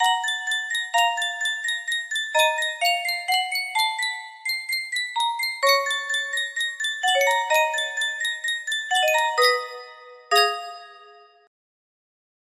Sankyo Music Box - A Night on Bald Mountain EDY music box melody
Full range 60